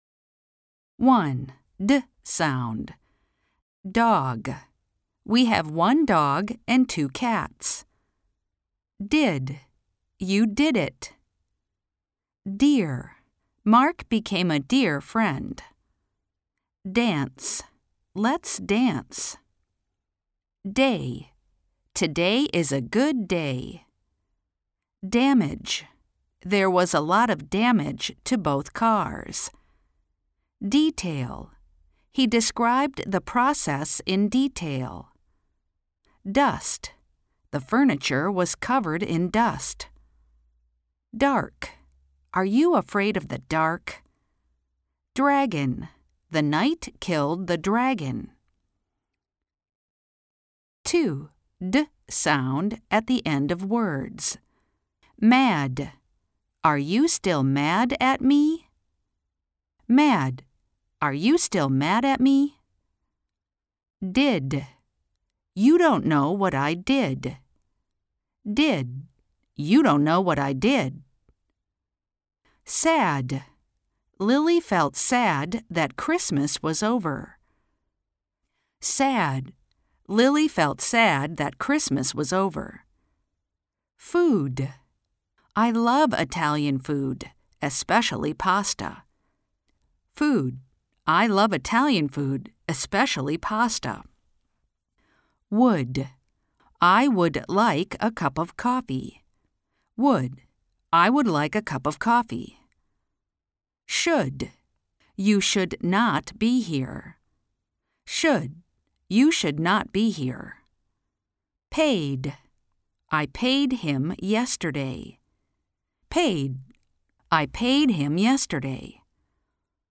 Os sons de [d] e [t].mp3